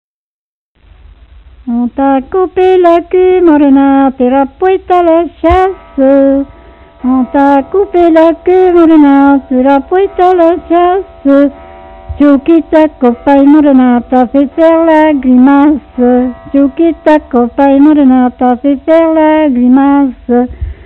Couplets à danser
branle : courante, maraîchine
Répertoire de chansons populaires et traditionnelles
Pièce musicale inédite